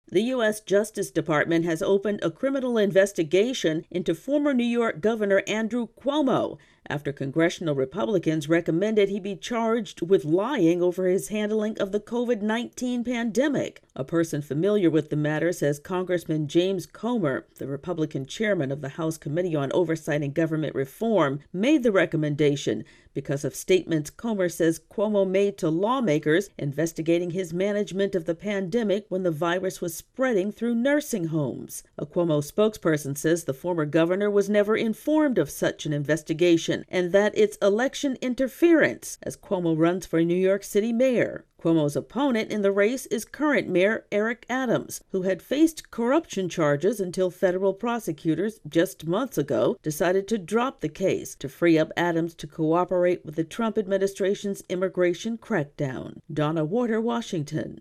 reports on a criminal investigation of former New York Gov. Andrew Cuomo.